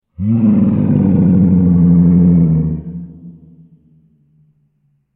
دانلود صدای شیر 3 از ساعد نیوز با لینک مستقیم و کیفیت بالا
جلوه های صوتی